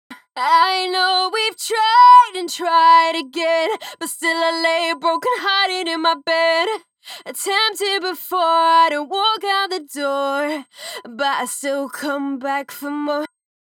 サウンドデモ
ボーカル（原音）
SA-3_FemaleVocal_Bypassed.wav